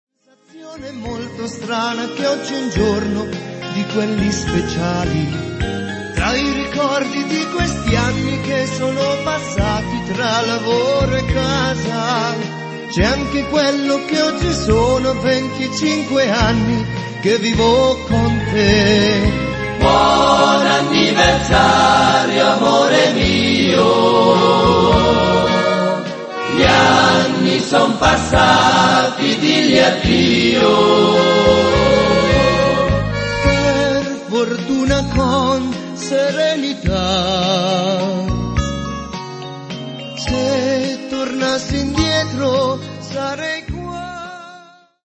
mazurca